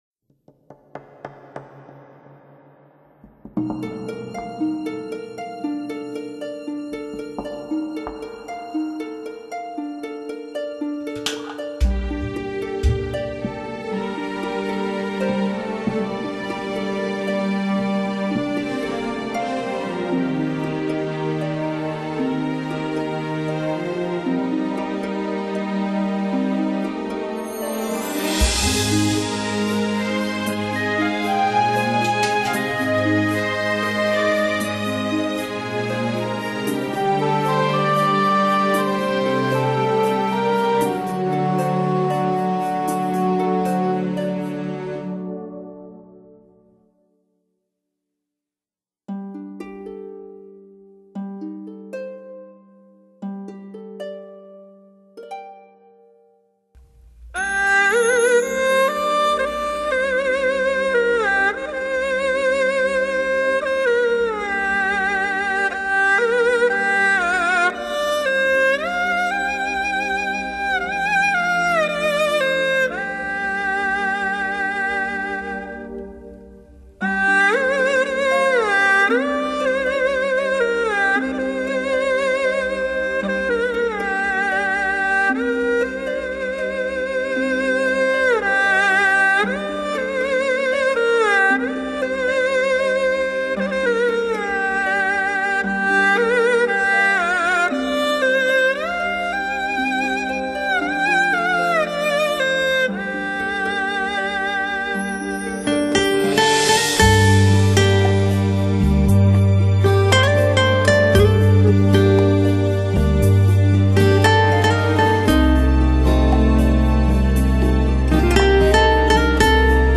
DSD